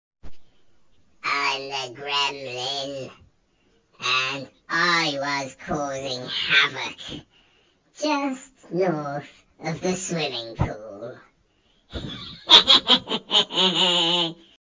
Listen to the gremlin
sport-centre-gremlin.mp3